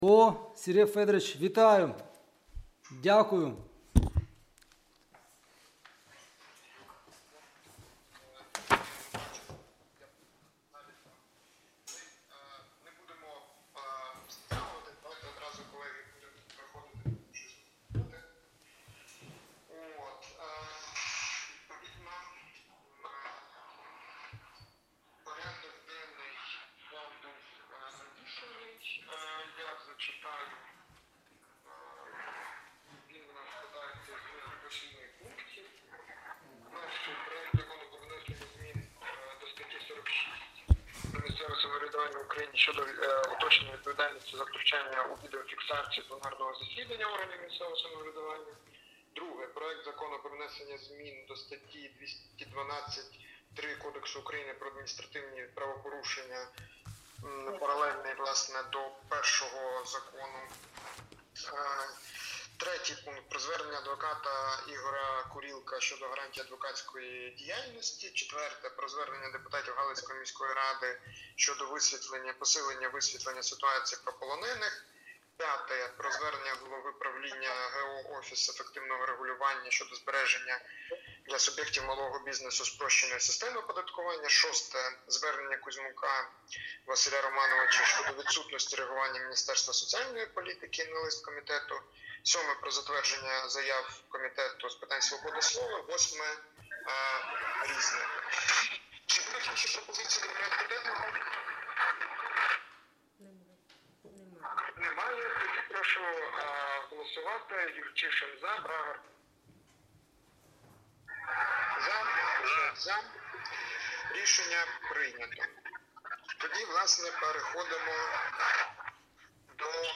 Аудіозапис засідання Комітету від 20 листопада 2024р.